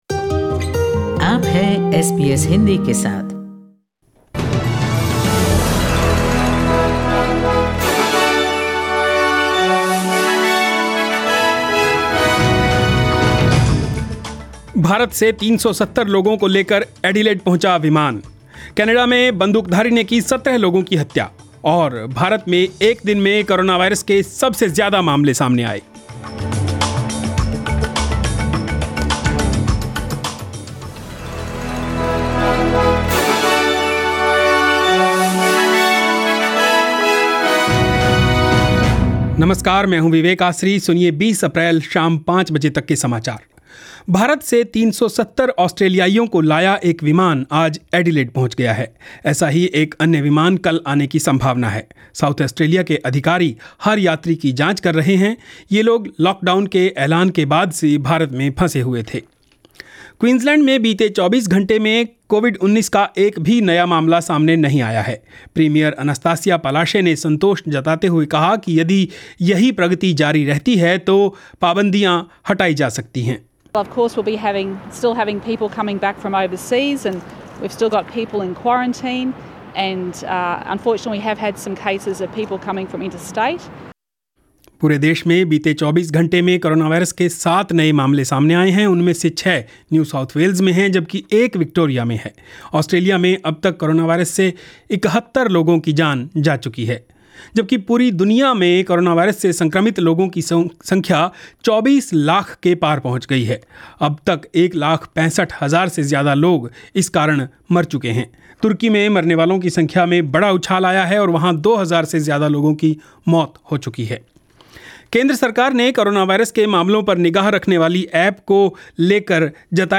Catch the latest news. In this bulletin** The first of two flights bringing Australian citizens home from India lands in Adelaide.